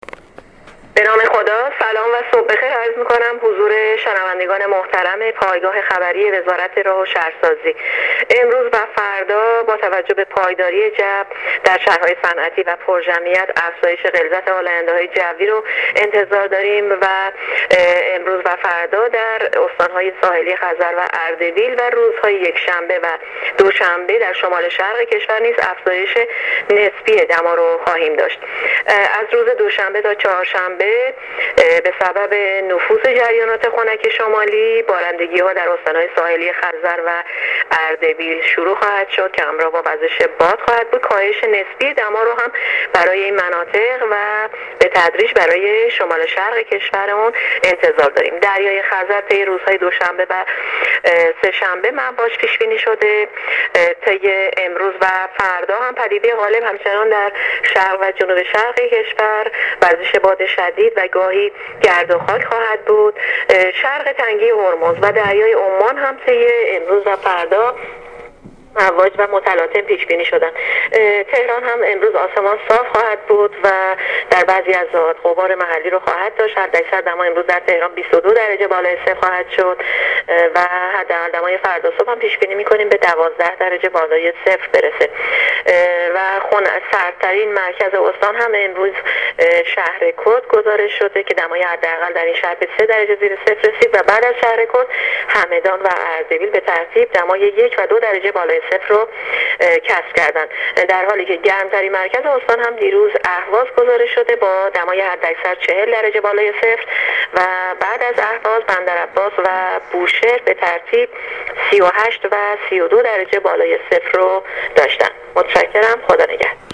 کارشناس سازمان هواشناسی کشور در گفت‌وگو با رادیو اینترنتی وزارت راه و شهرسازی، آخرین وضعیت آب و هوای کشور را تشریح کرد.
گزارش رادیو اینترنتی از آخرین وضعیت آب‌‌و‌‌‌هوای ۳ آبان